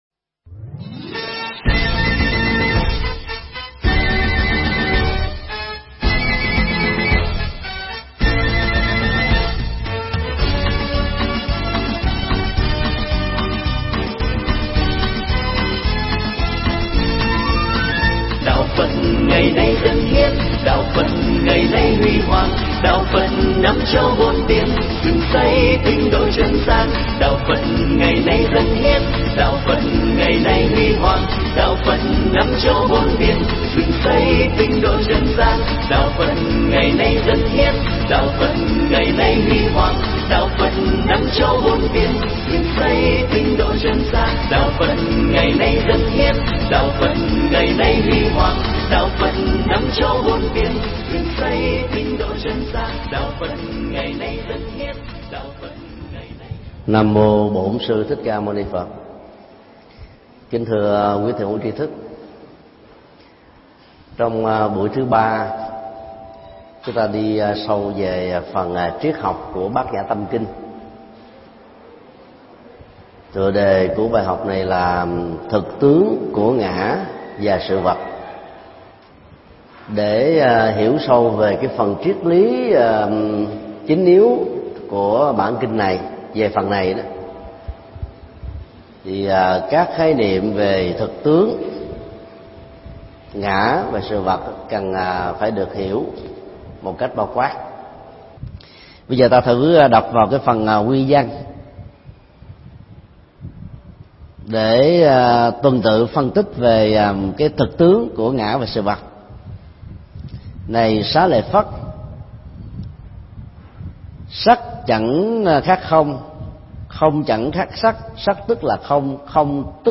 Tải mp3 Pháp thoại Tâm Kinh 3: Cắt lớp cái tôi được thầy Thích Nhật Từ thuyết pháp chùa Xá Lợi ngày 27 tháng 12 năm 2009